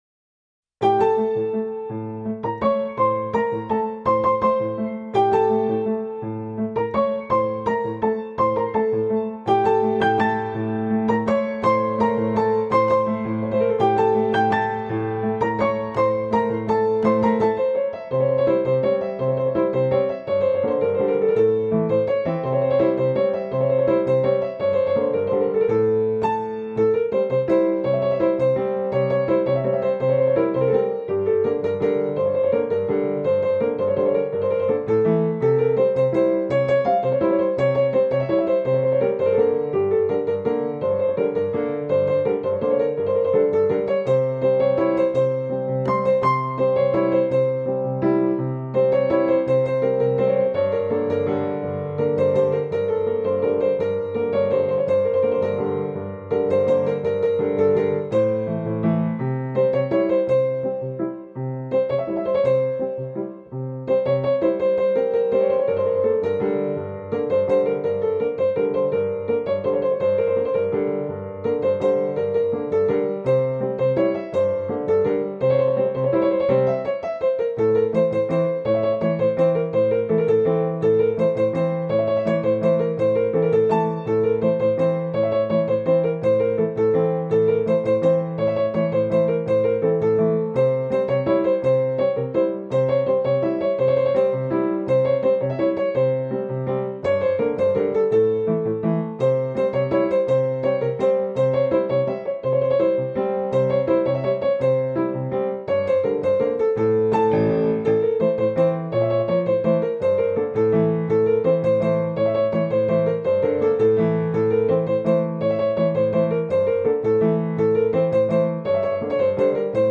با پیانو